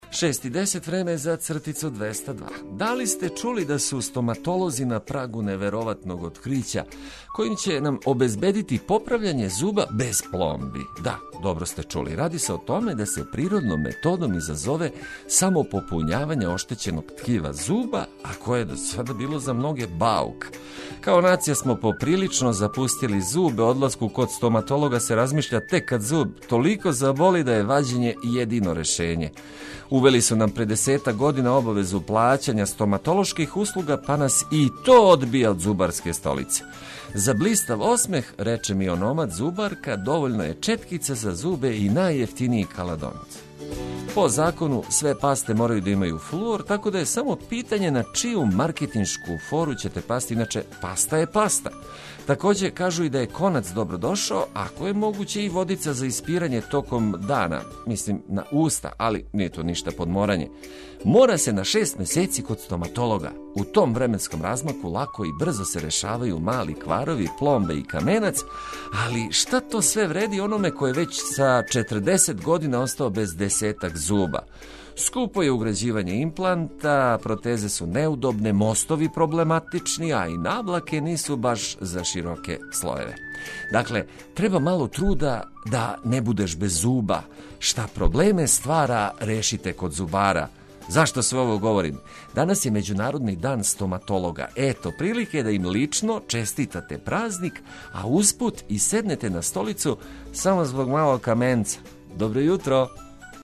Јутро ћемо дочекати уз лепе приче проткане ведром музиком и тако заједно почети дан.